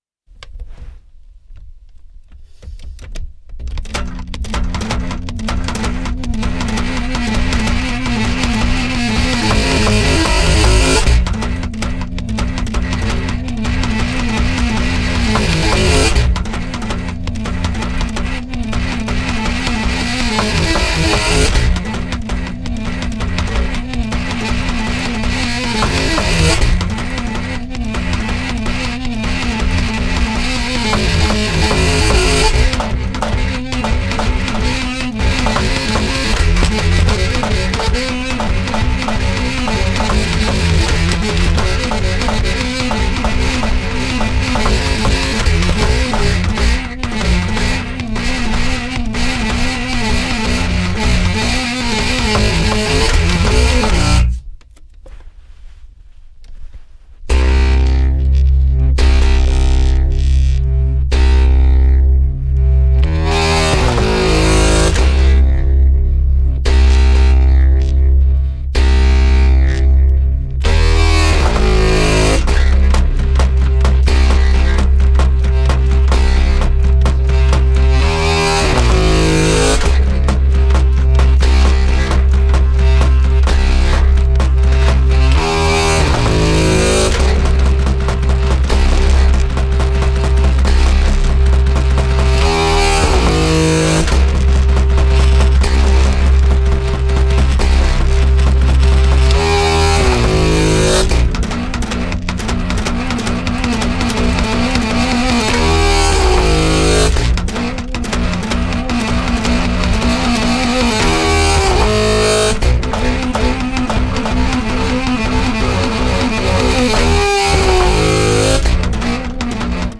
ジャズや現代音楽、ロックやドローン等を、バス・サックスの強烈な音色で体現した極上のミニマル作品！